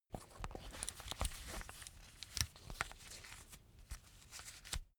Royalty free sounds: Belt